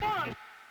SG - Vox 7.wav